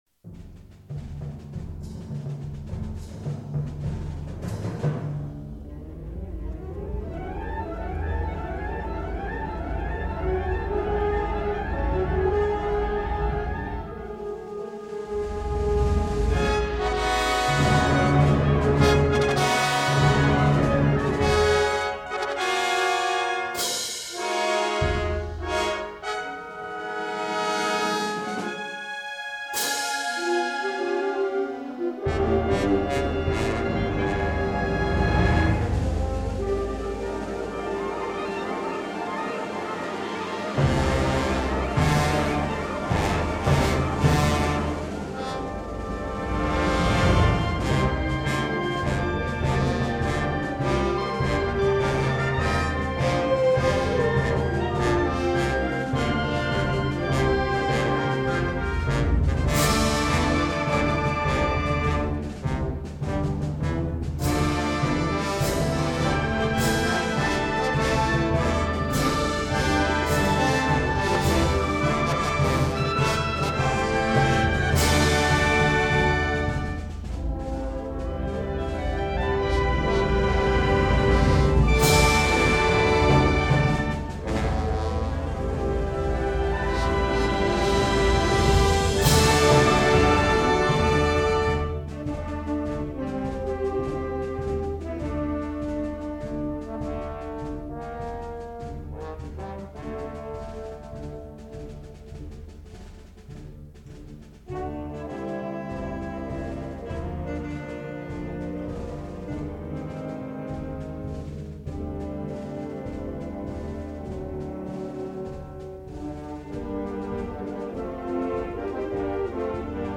Symphonic Christian March
Gender: Christian marches